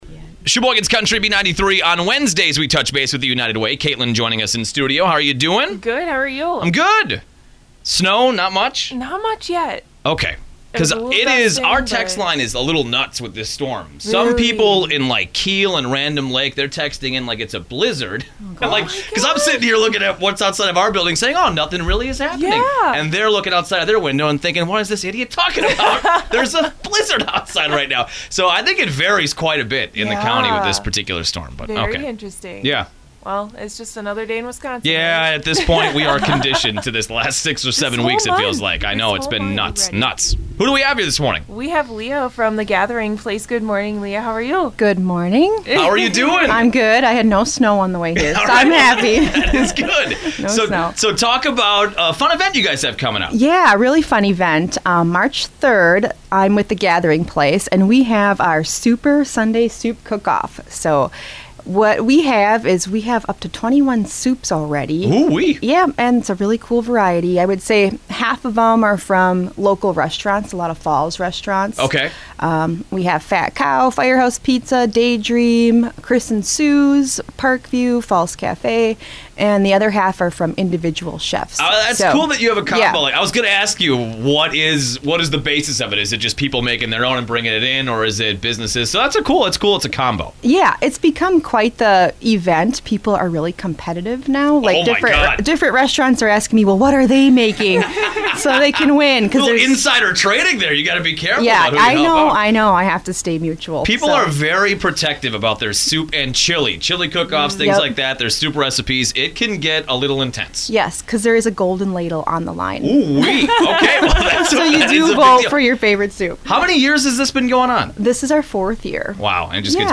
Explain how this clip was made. Special thanks to Midwest Communications for hosting United Way of Sheboygan County on the radio every week to highlight various volunteer opportunities across the county.